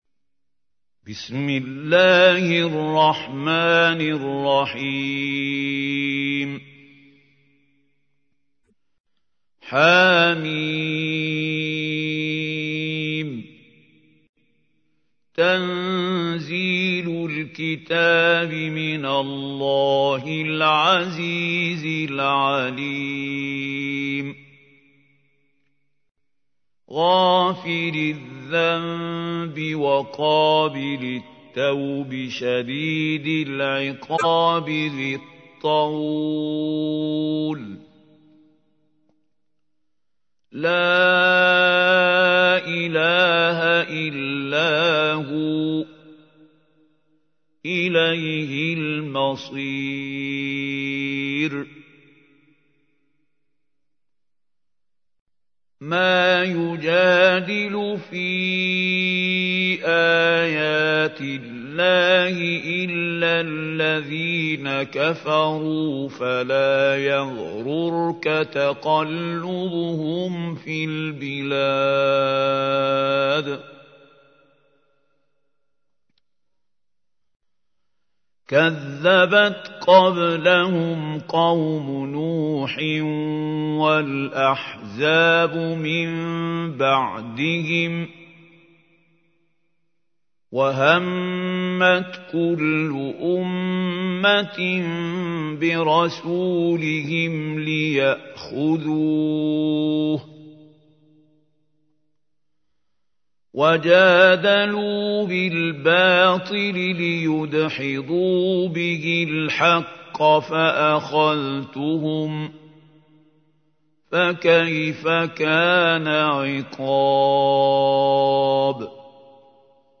تحميل : 40. سورة غافر / القارئ محمود خليل الحصري / القرآن الكريم / موقع يا حسين